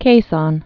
(kāsôn, -sōn)